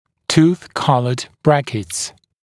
[tuːθ ‘kʌləd ‘brækɪts][ту:с ‘калэд ‘брэкитс]брекеты в цвет зубов